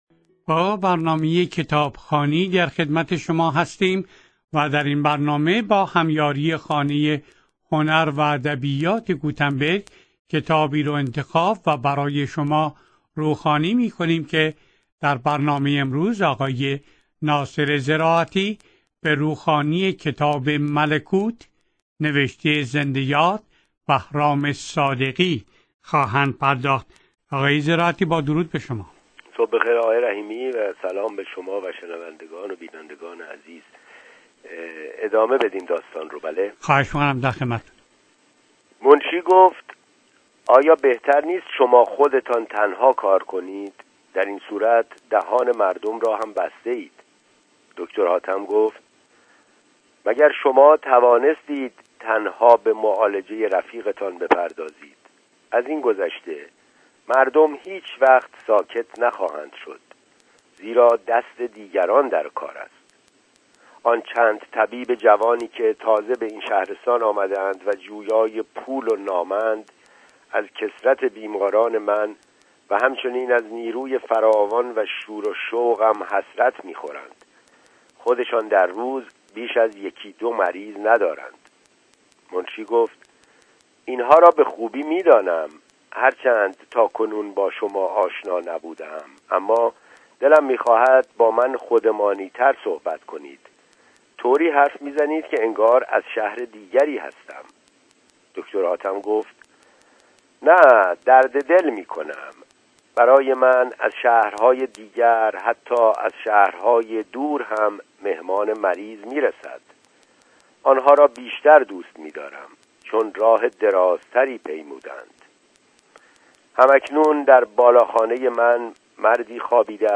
در رنامه کتابخوانی رادیو سپهر روخوانی شده و به یادگار در وبسایت رادیو پیام قرار گرفته است.